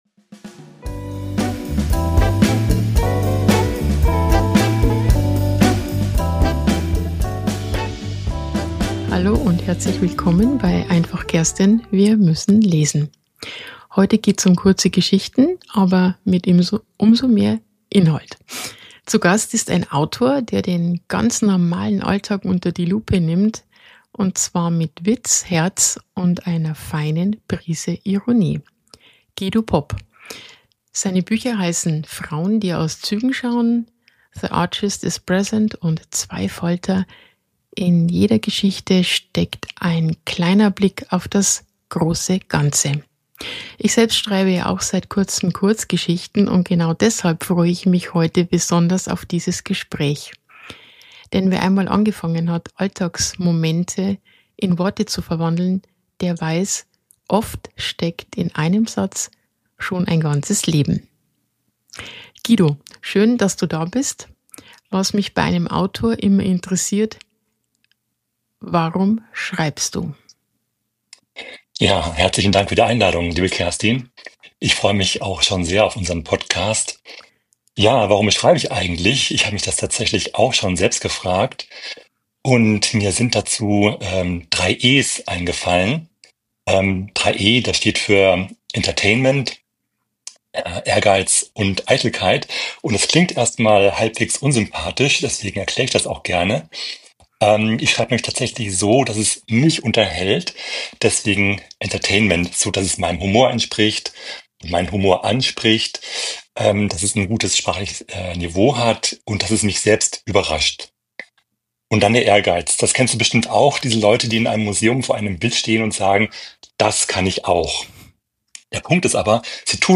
Autorenintervie